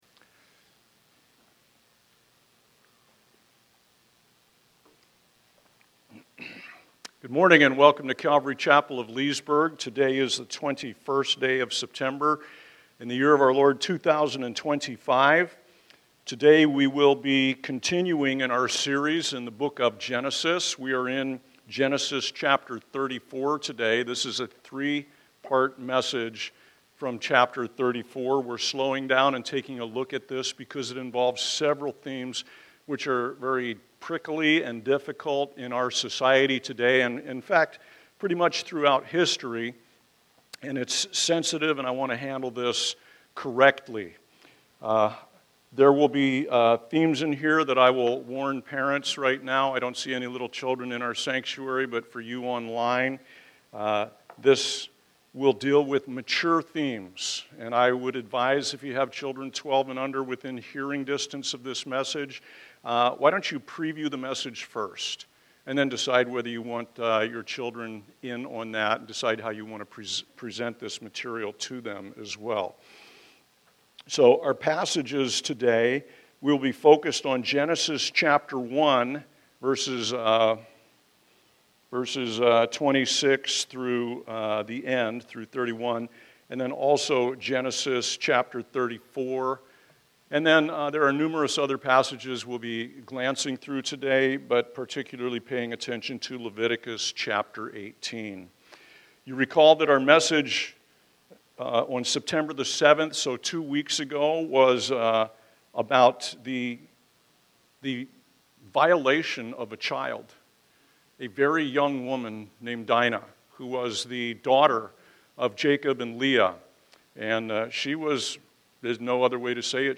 by Calvary Chapel Leesburg | Sep 21, 2025 | Sermons